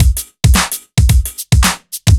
OTG_Kit 3_HeavySwing_110-B.wav